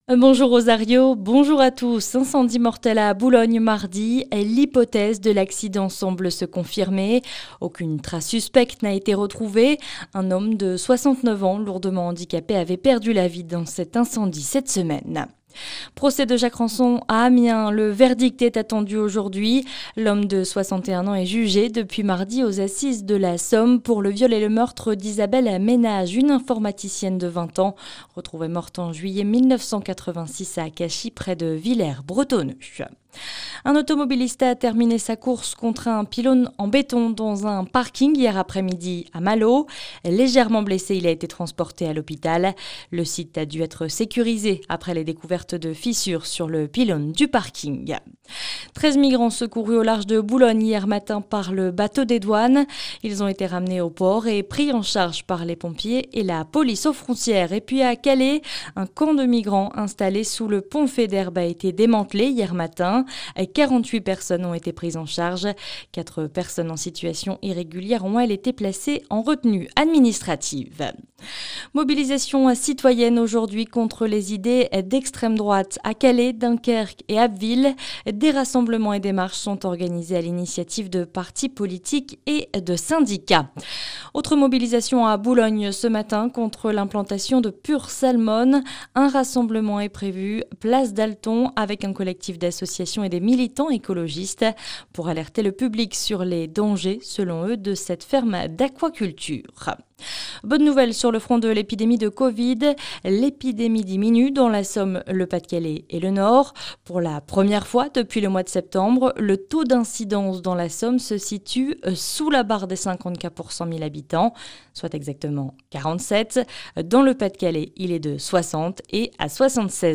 Le journal du samedi 12 juin sur la Côte d'Opale, Picarde, le région d'Abbeville et dans les Flandres Maritimes, en 5 minutes